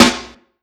Snares
Medicated Snare 30.wav